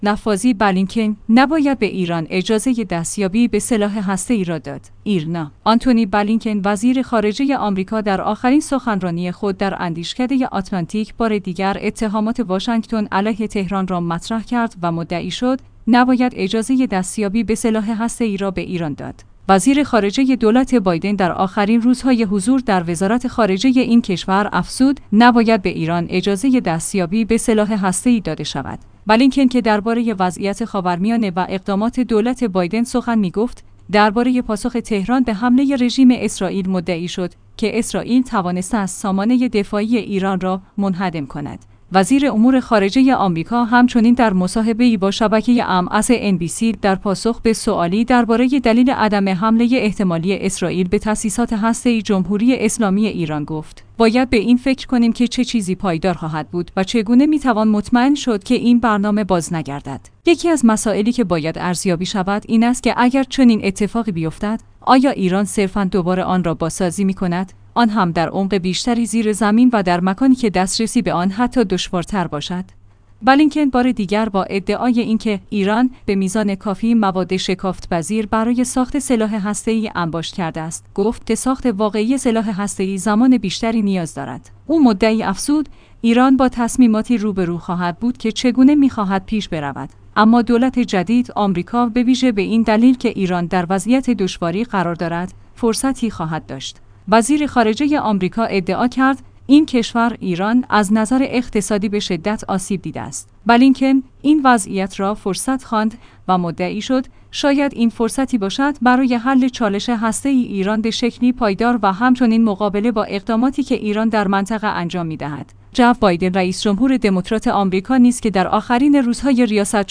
ایرنا/ آنتونی بلینکن وزیر خارجه امریکا در آخرین سخنرانی خود در اندیشکده آتلانتیک بار دیگر اتهامات واشنگتن علیه تهران را مطرح کرد و مدعی شد: نباید اجازه دستیابی به سلاح هسته ای را به ایران داد.